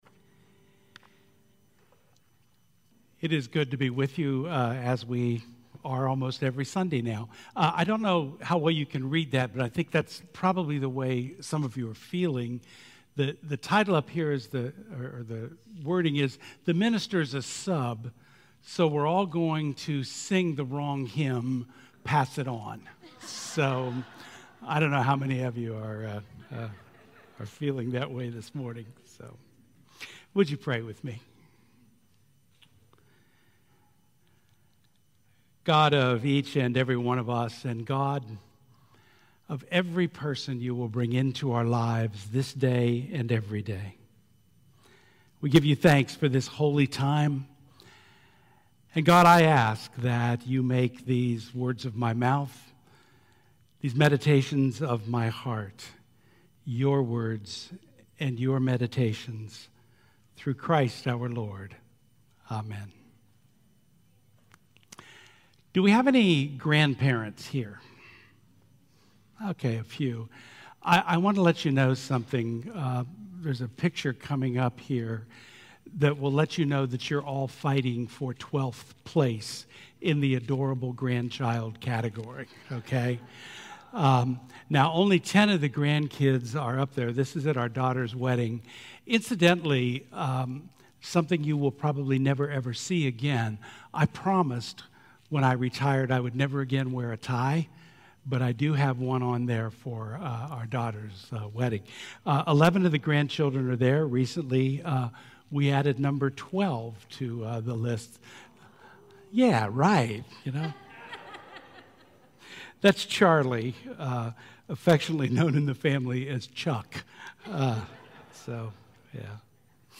June 23, 2024 Sermon